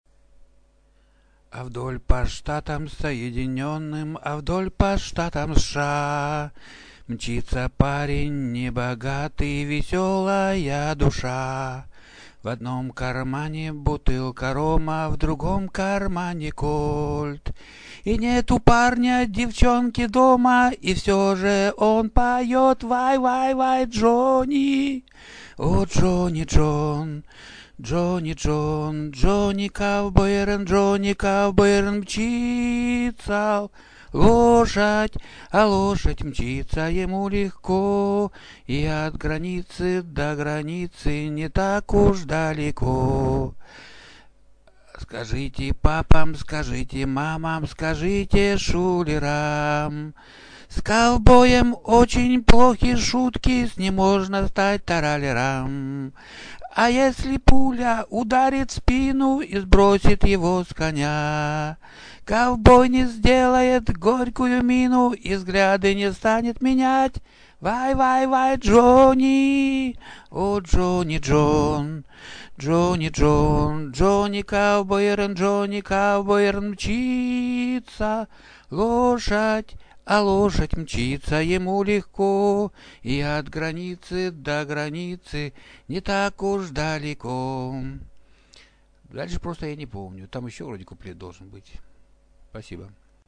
Я тут намурчал её как помню.